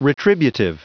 Prononciation du mot retributive en anglais (fichier audio)
Prononciation du mot : retributive